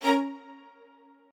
admin-leaf-alice-in-misanthrope/strings34_1_012.ogg at a8990f1ad740036f9d250f3aceaad8c816b20b54